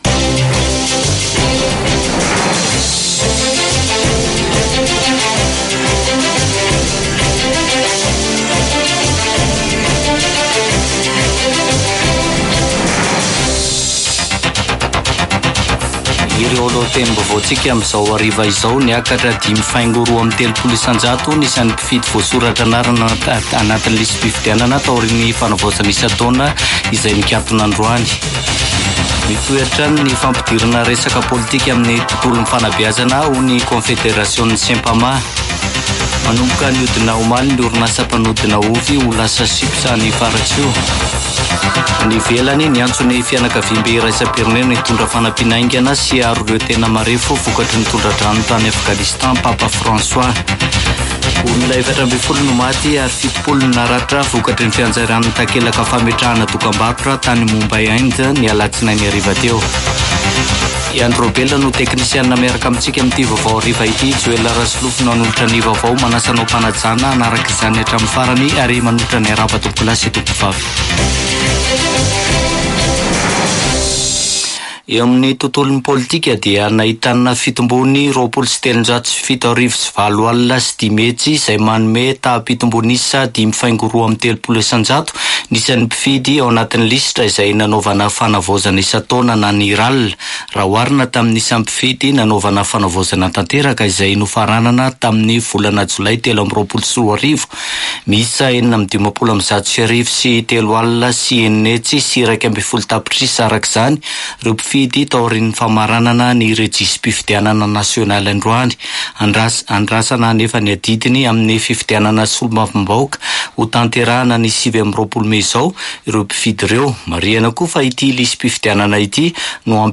[Vaovao hariva] Alarobia 15 mey 2024